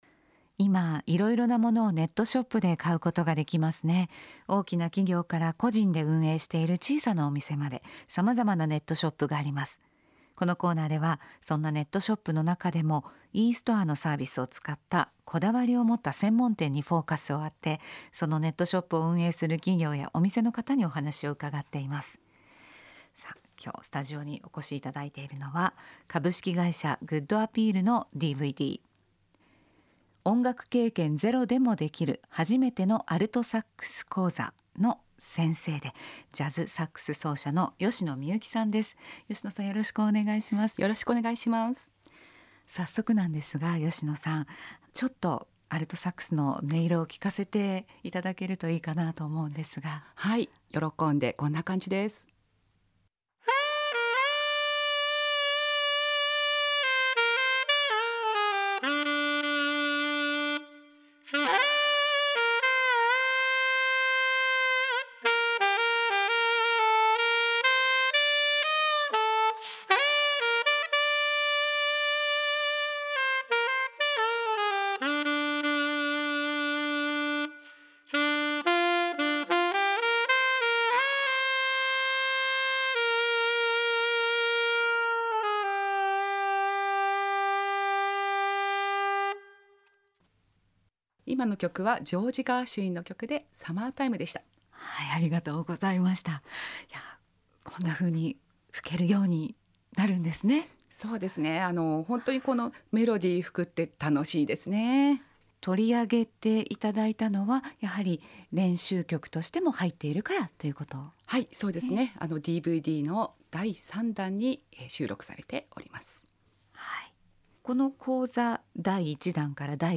FM横浜のラジオ番組にも出演しています。